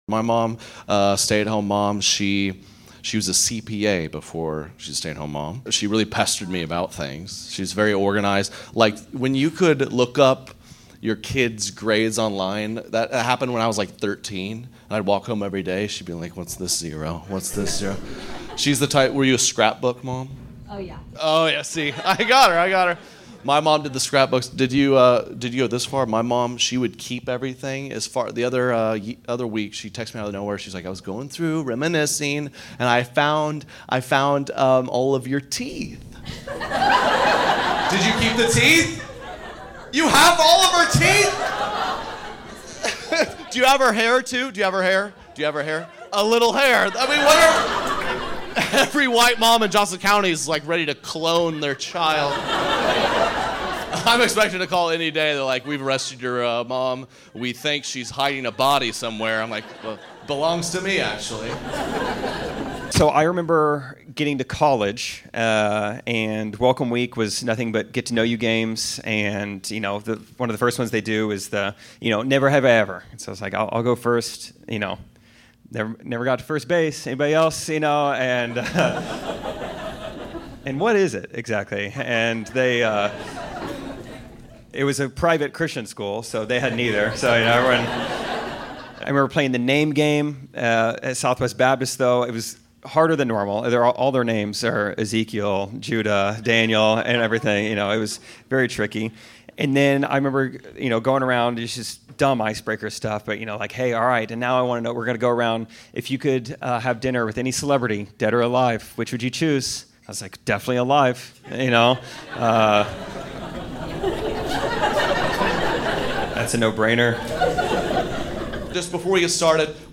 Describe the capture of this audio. We have a live Kansas City audience on this week's podcast episode.